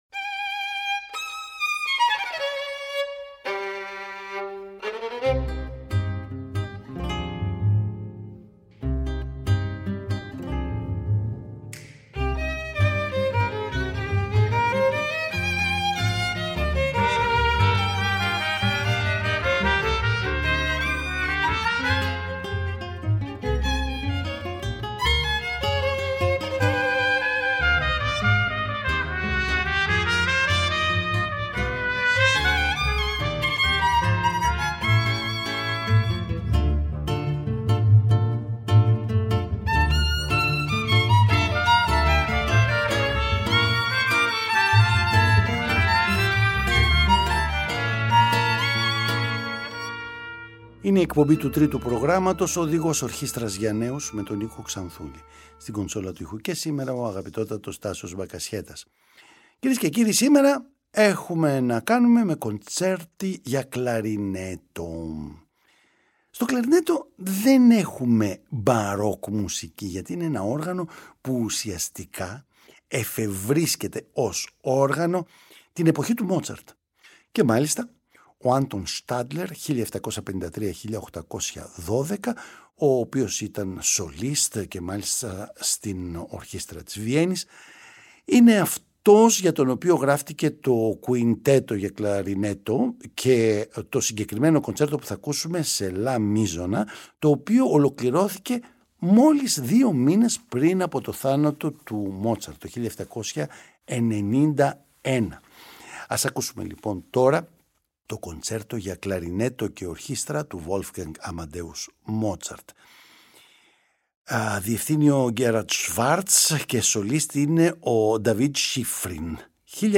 Κοντσέρτα για τα Όργανα της Συμφωνικής Ορχήστρας: Κλαρινέτο